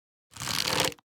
Minecraft Version Minecraft Version snapshot Latest Release | Latest Snapshot snapshot / assets / minecraft / sounds / item / crossbow / quick_charge / quick1_1.ogg Compare With Compare With Latest Release | Latest Snapshot